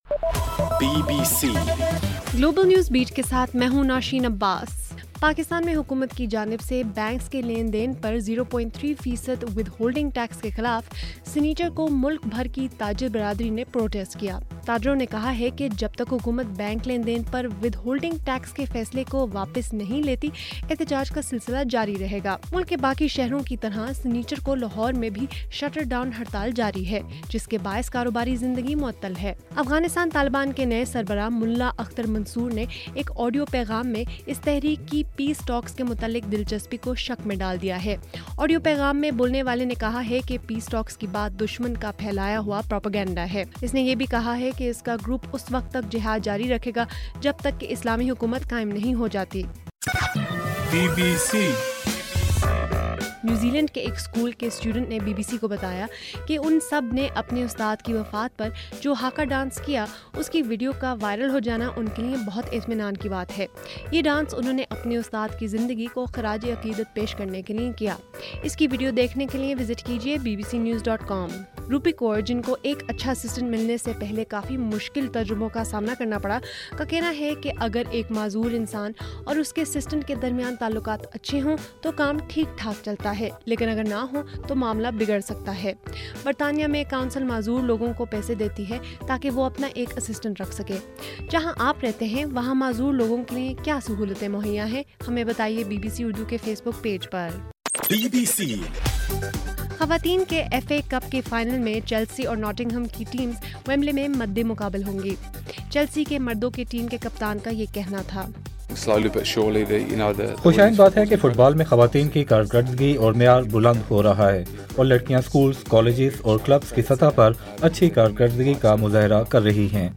اگست 2: صبح1 بجے کا گلوبل نیوز بیٹ بُلیٹن